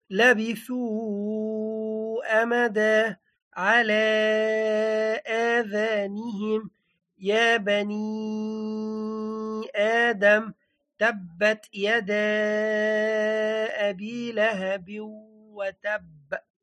• The sound is elongated by 4 or 5 counts.